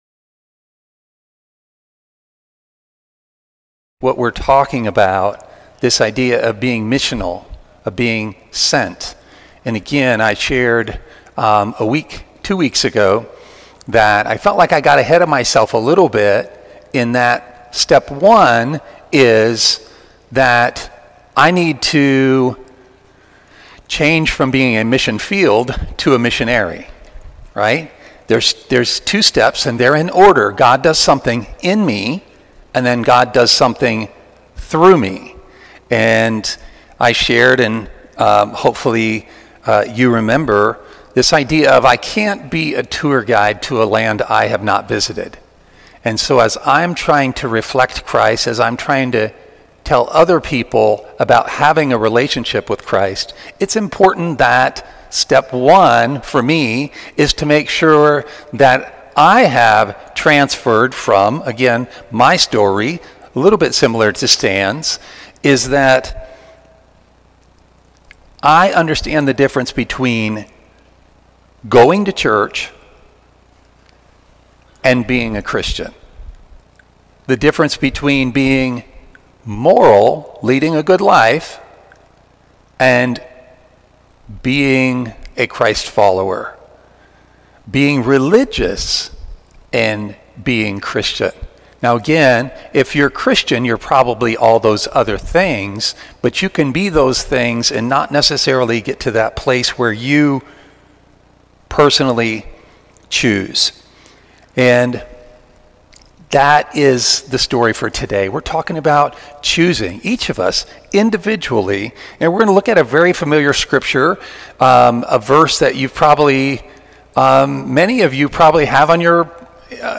sermon-4-12-26-C.mp3